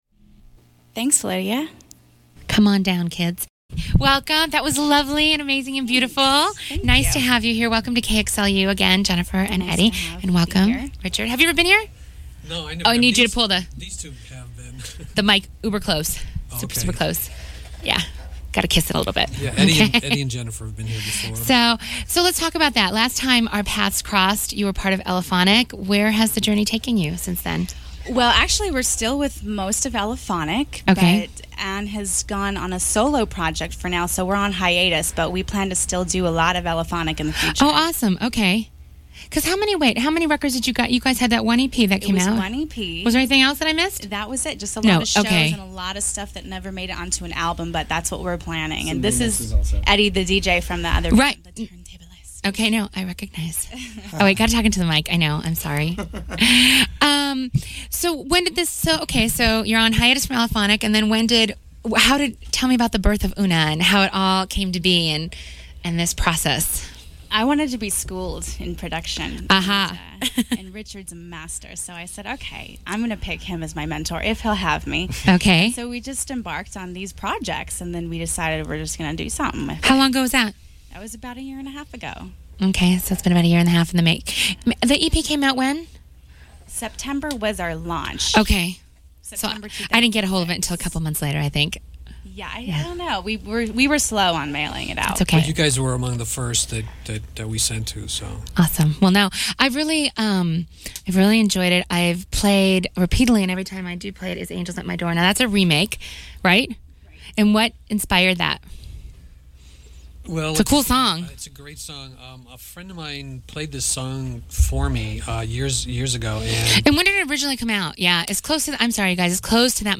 KXLU Interview
01-KXLU-Interview.mp3